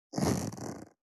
426,ジッパー,チャックの音,洋服関係音,ジー,バリバリ,カチャ,ガチャ,シュッ,パチン,ギィ,
ジッパー